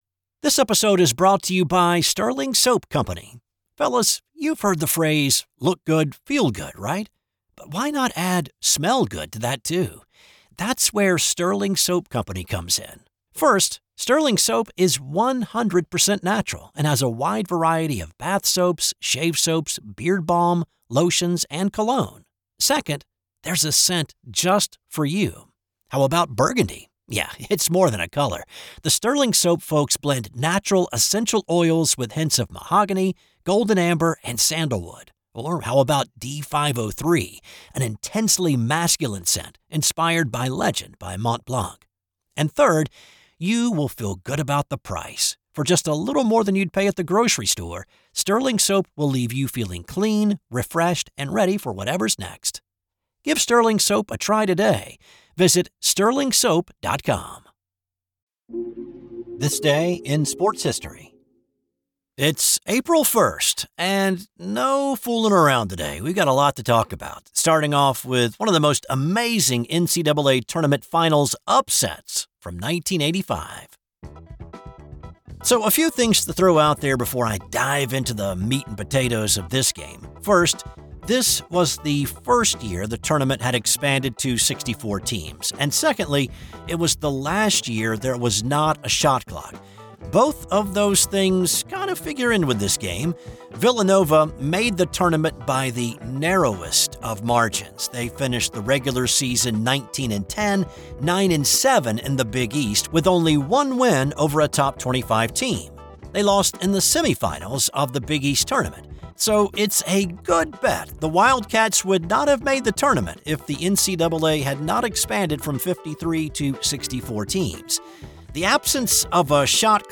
I research, write, voice, and produce each show.